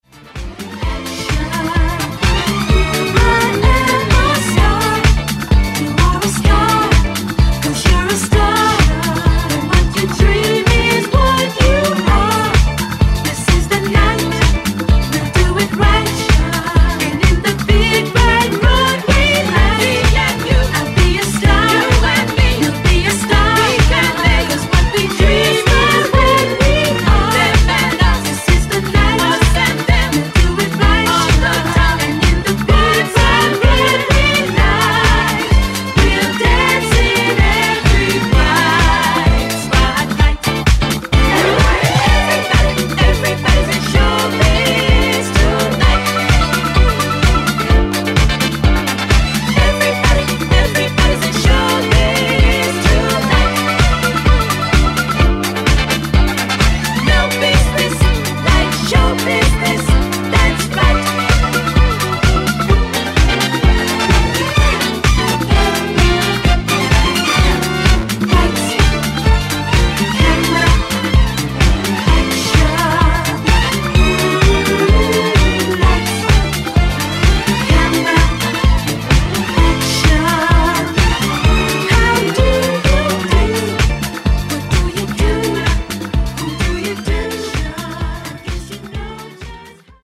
Genre: 80's Version: Clean BPM